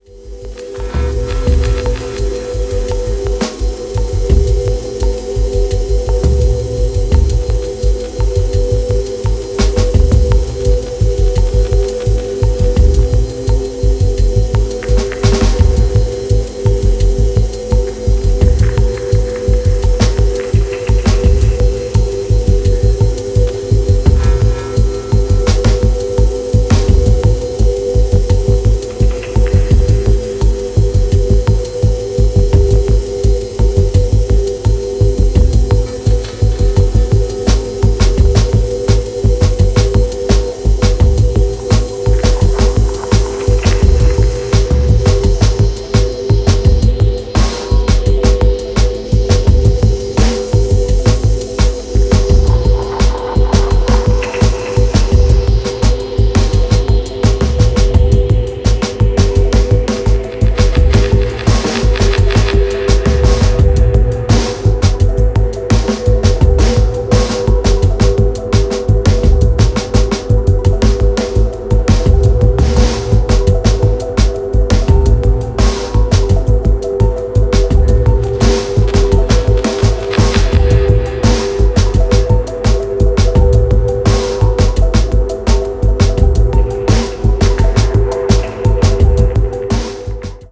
ジャジーなブレイクビートの生々しい躍動感が切れ味鋭い
非常に先進的、かつ神聖さすら感じさせるDNB表現を堂々開陳